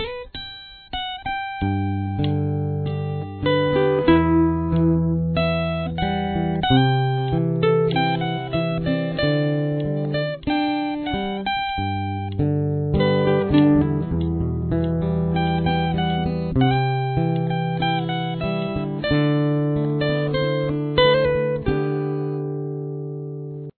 Here is what the full intro sounds like together :